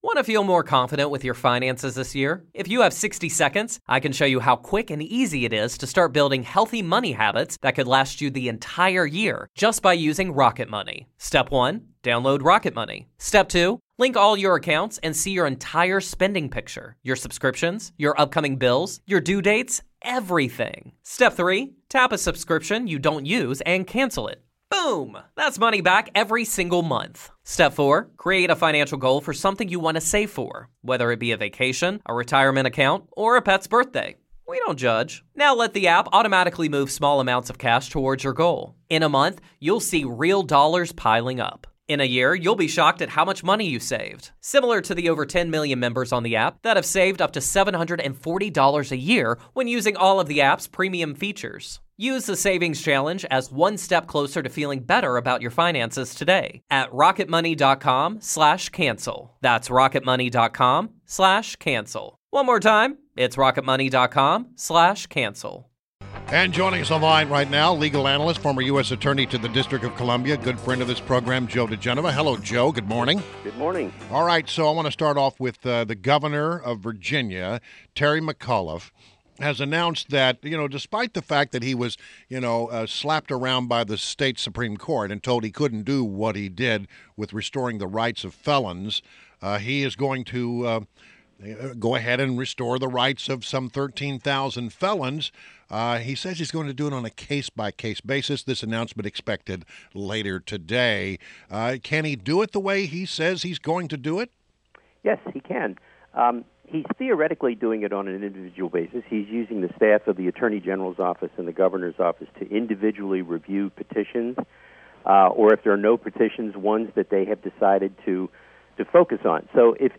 WMAL Interview - JOE DIGENOVA - 08.22.16